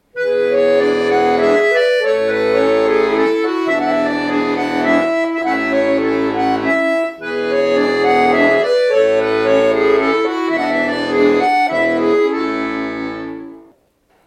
Das Duo "Donna & Giovanna" spielte einen kleinen Ausschnitt eines ... Ländlers?